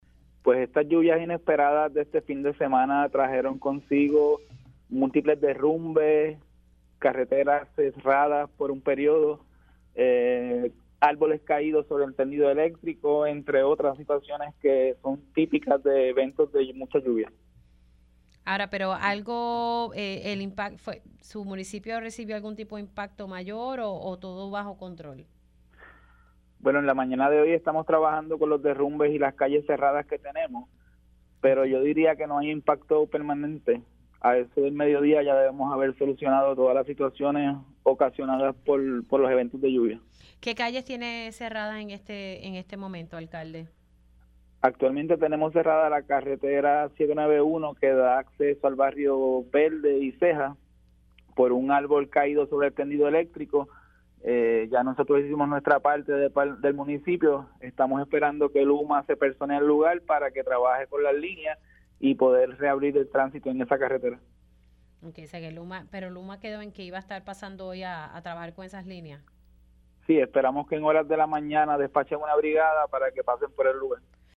A este mediodía ya debemos haber solucionado todas las situaciones ocasionadas por los eventos de lluvia“, indicó el alcalde de Comerío, Irving Rivera, en Pega’os en la Mañana.
107-IRVING-RIVERA-ALC-COMERIO-ATIENDE-DERRUMBES-ARBOLES-CAIDOS-Y-CARRETERAS-CERRADAS-POR-LLUVIAS-DEL-FIN-DE-SEMANA.mp3